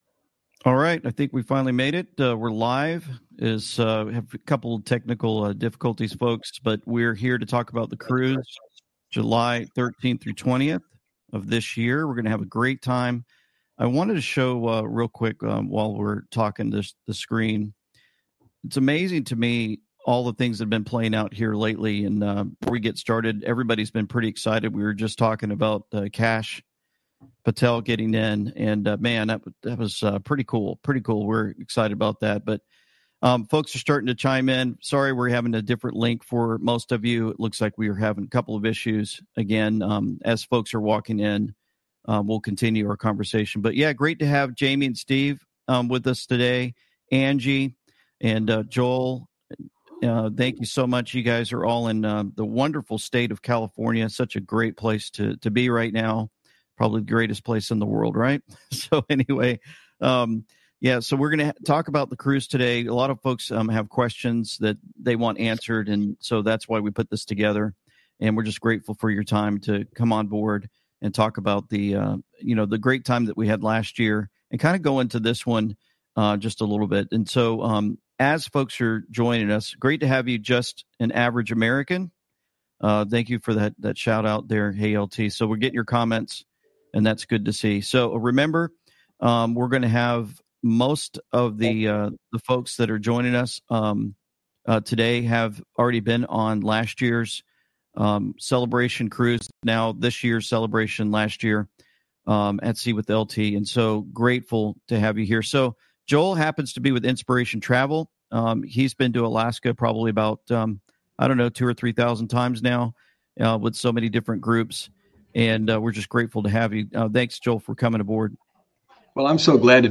The And We Know text is about a group discussing their upcoming cruise from July 13th to 20th.